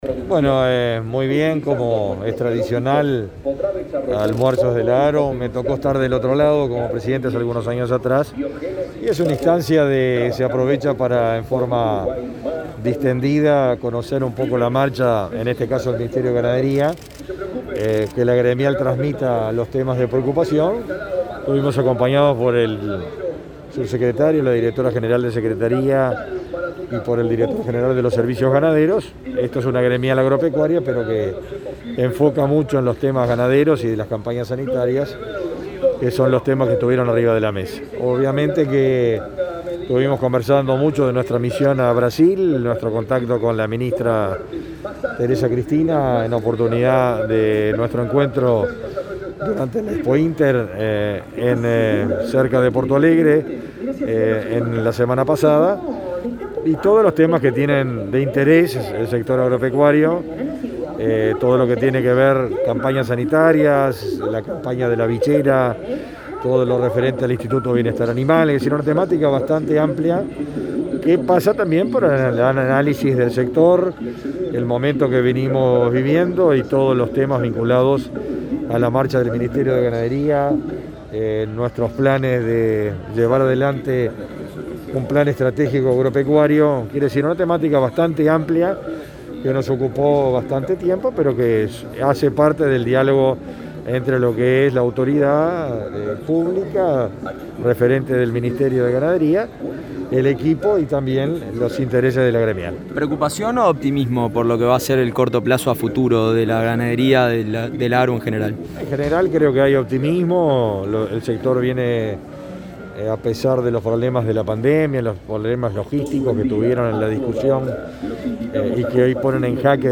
Declaraciones de prensa del ministro de Ganadería, Fernando Mattos
Al finalizar, el ministro Mattos efectuó declaraciones a la prensa.